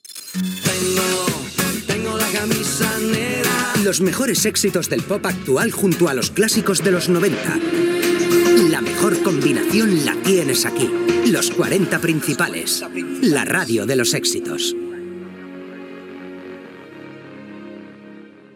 Indicatiu de la cadena